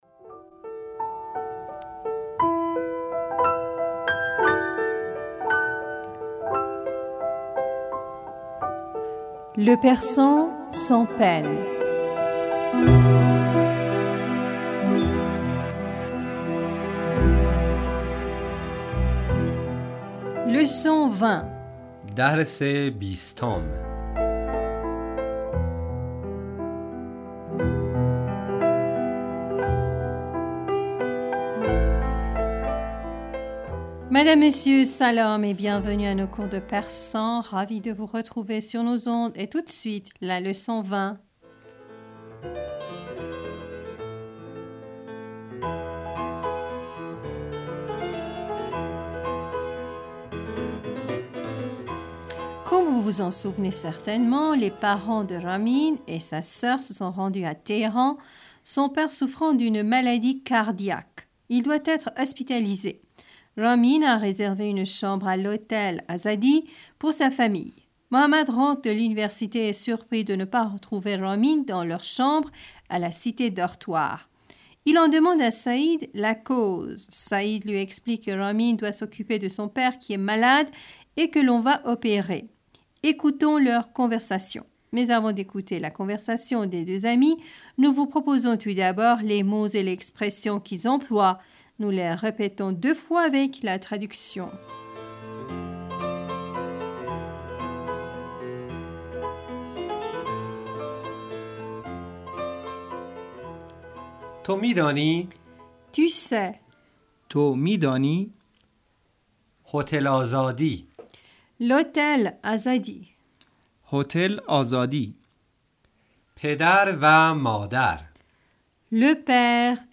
Nous les répétons deux fois, avec la traduction.